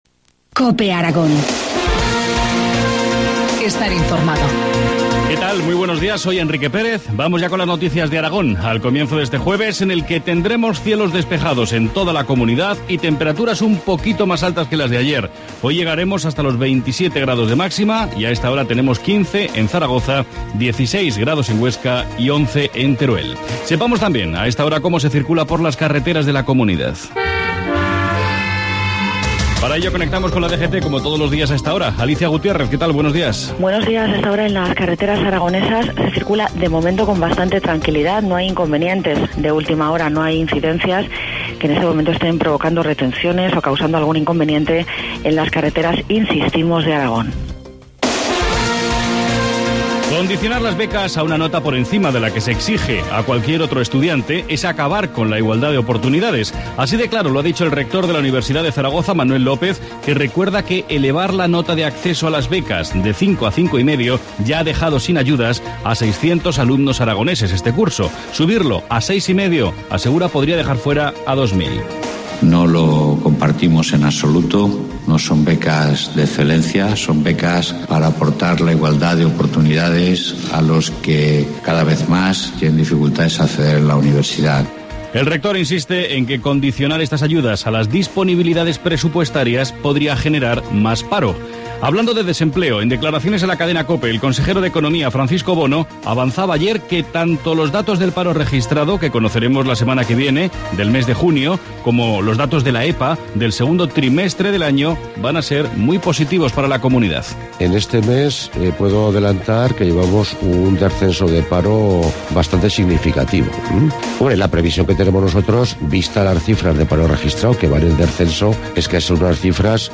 Informativo matinal, 27 junio, 7,25 horas